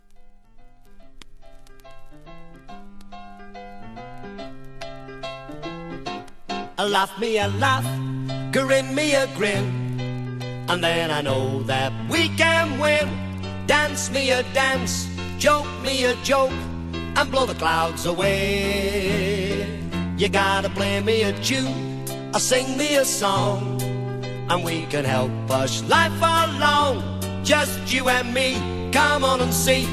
• TV Soundtrack